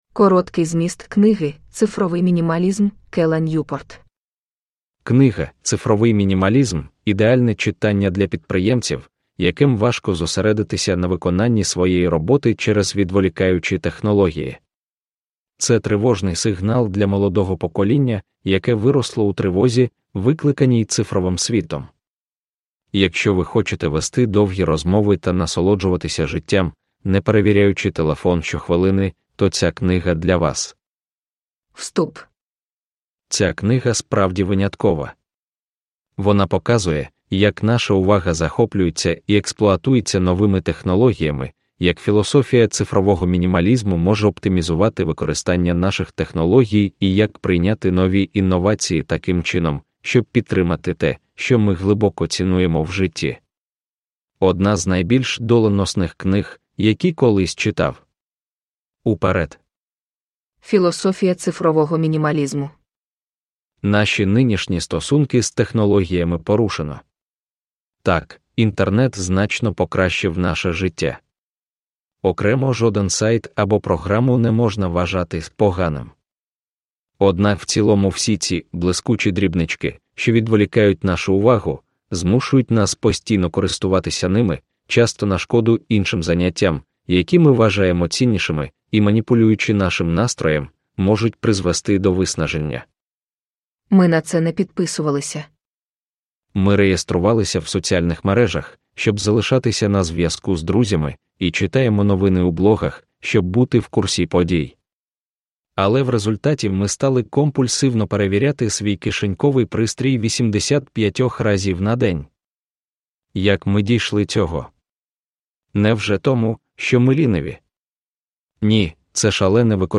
Digital Minimalism – Ljudbok – Laddas ner
AI berättad sammanfattning av Digital minimalism är en livsfilosofi om teknikanvändande där du väljer att fokusera dina aktiviteter online på de som stöder det du vill i livet.
Uppläsare: Reedz Audiobooks